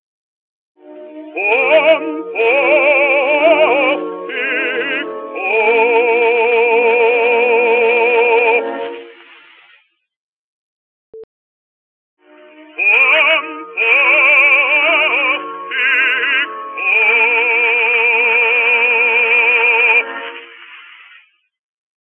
Transferring 78 RPM Records - Buzzing?
I’ve been having a small problem involving some (but not all) vocal records - at certain frequencies, there’s a buzzing noise clearly set off by the singer.
I’d be tempted to chop-off just about everything above 3kHz using the equalizer , as beyond 3kHz it’s mostly noise … 78-3K equalization 'curve'.png654×528 15.7 KB 78-3K equalization ‘curve’.xml (339 Bytes) /uploads/default/original/2X/6/65d8a6bb0650e02475ac1a0028c2e10f05e96690.wav